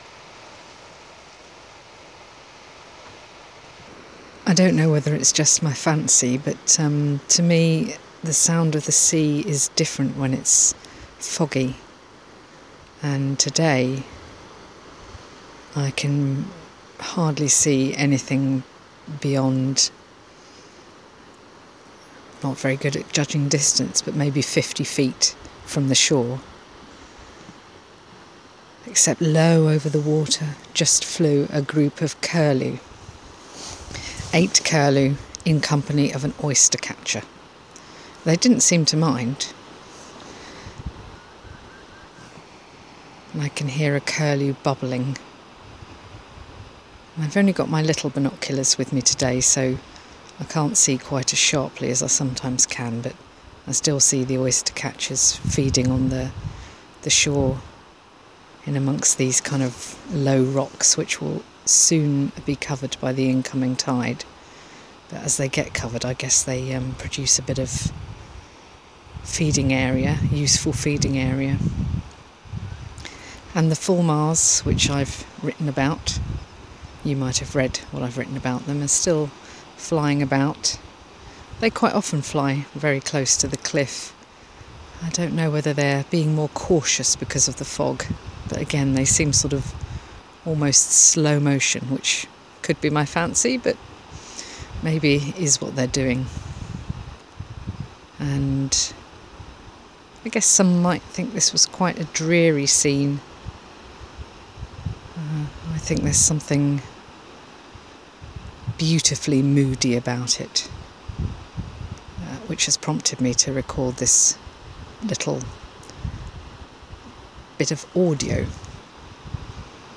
However, the wait is over and I give you… a mini – or maybe that should be nano – podcast about the joy of gazing at the sea on a foggy day!
foggy-day-whiteness-3-mar-21.mp3